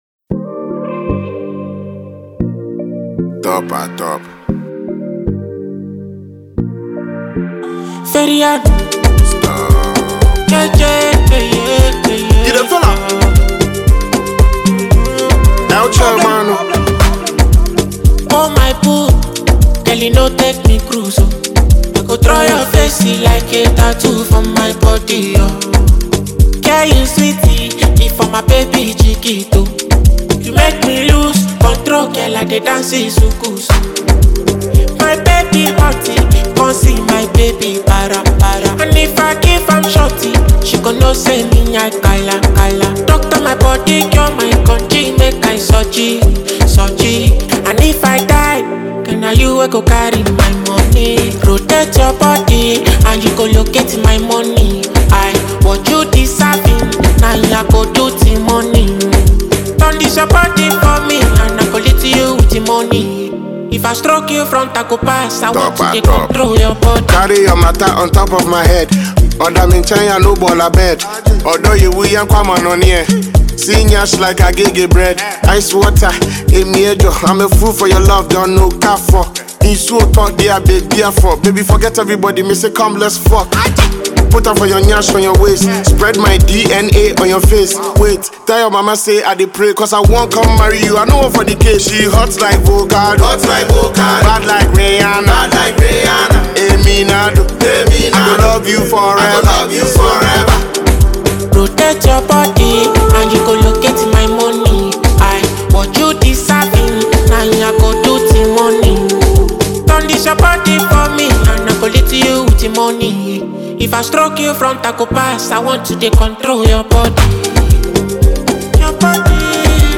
a talented Afrobeats artist